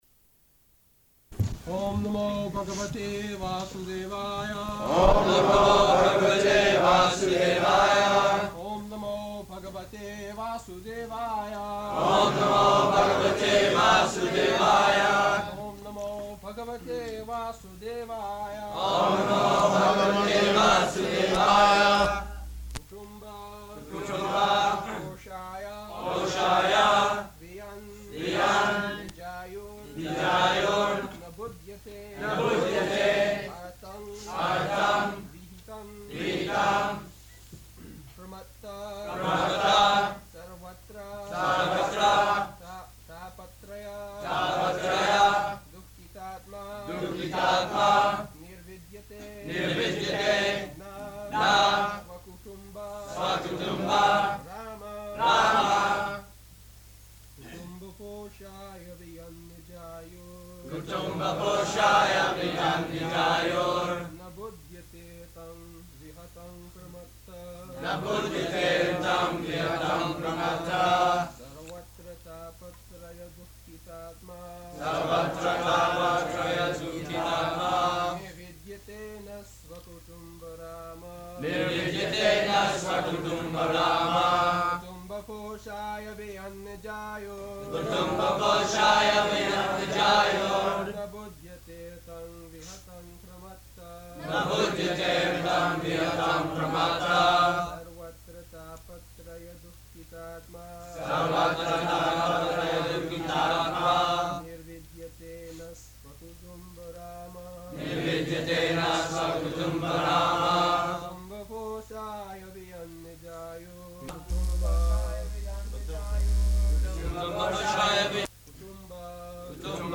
June 28th 1976 Location: New Vrindavan Audio file
[Prabhupāda and devotees repeat] [leads chanting, etc.]